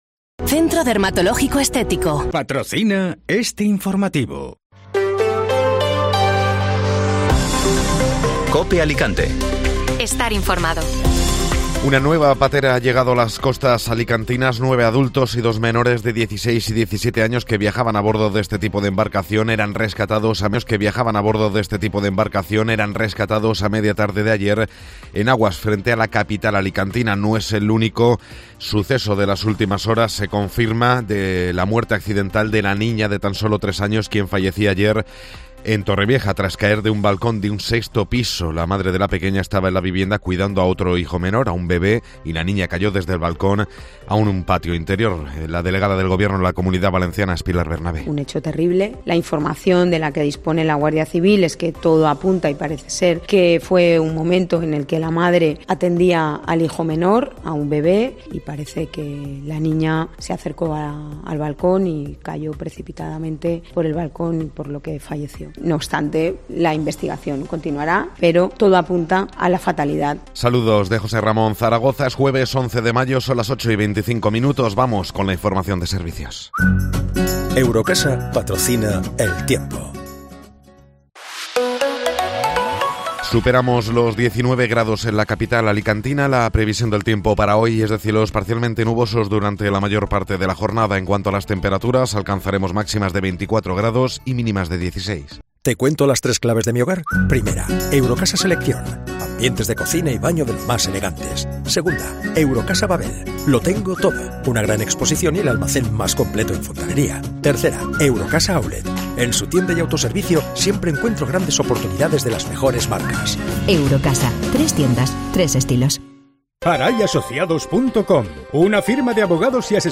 Informativo Matinal (Jueves 11 de Mayo)